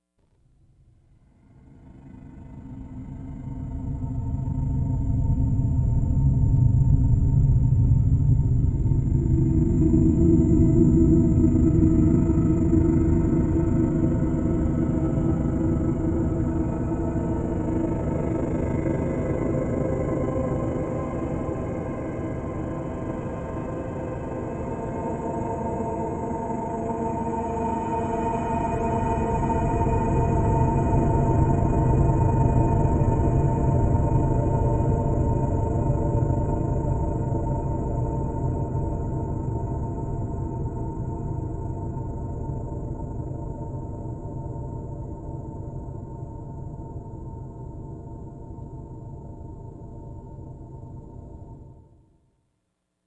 Stereo Reduction